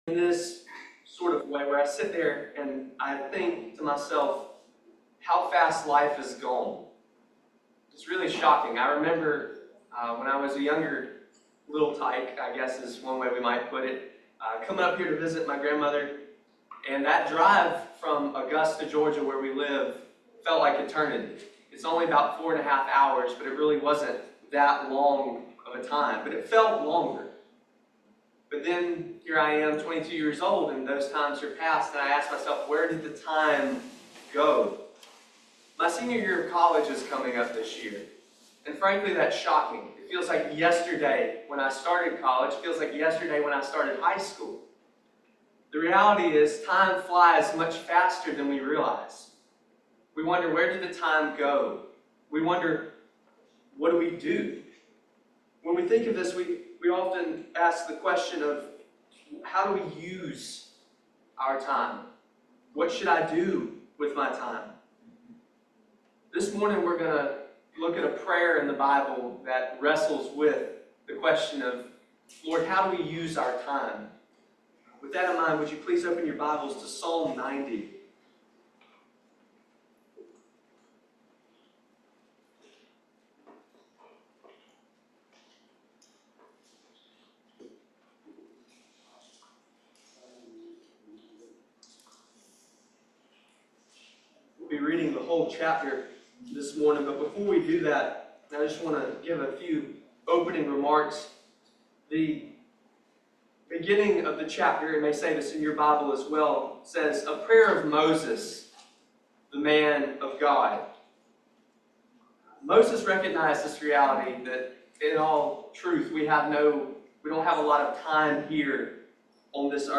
Psalms 90 Service Type: Family Bible Hour Seek God’s wisdom to use time wisely.